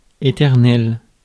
Ääntäminen
France: IPA: [e.tɛʁ.nɛl]